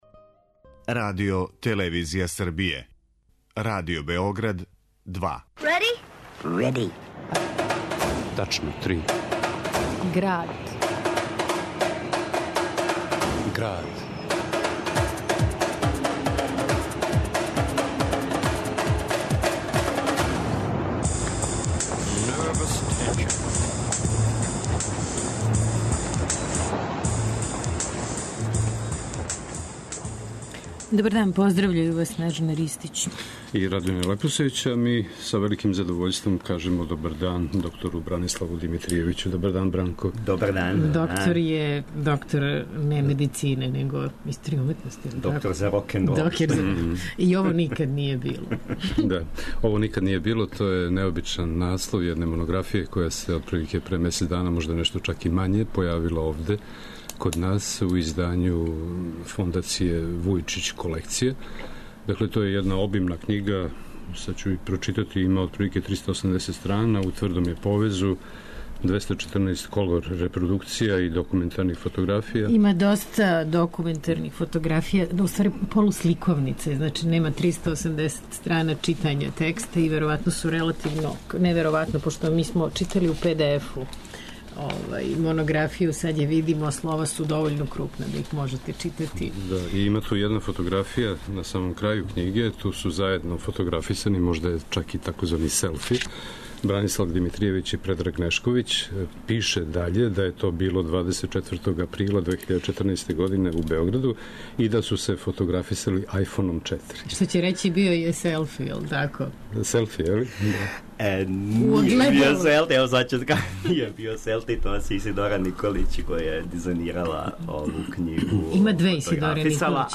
снимљене у уметниковом атељеу